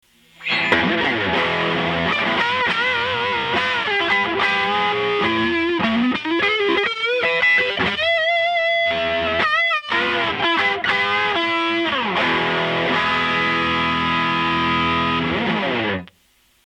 ザック・ワイルドのギターサウンドを、MXRが最新テクノロジーでデザインしたモデル。強烈なロングサスティーンリードトーンを生み出す。